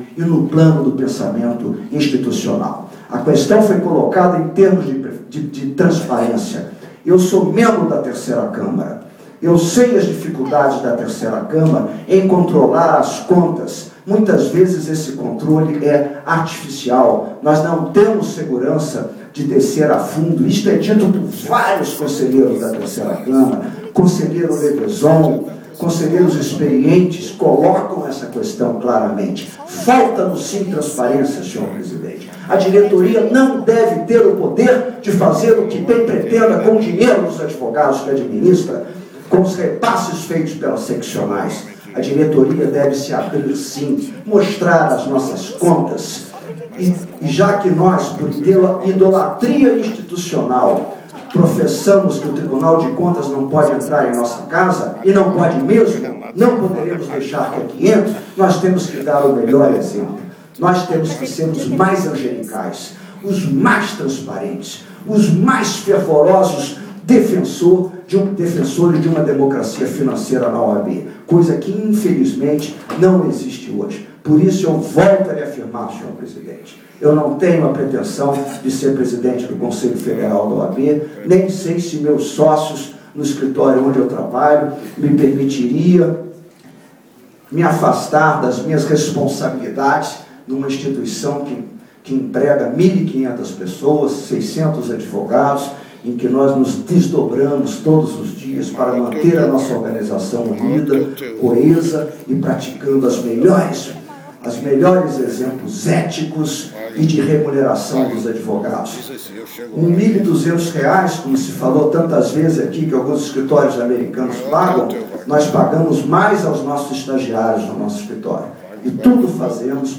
Debate acalorado no Conselho Federal da OAB
O Conselho Federal da OAB realiza desde ontem, e até hoje, sua sessão plenária do mês, sob a condução de seu presidente, Ophir Cavalcante, com participação dos 81 conselheiros da entidade.